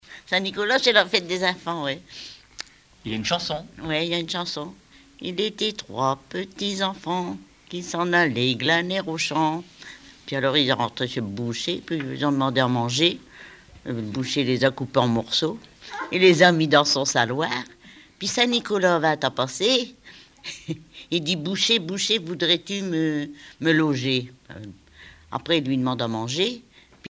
Témoignages liés aux rituels du calendrier
Catégorie Témoignage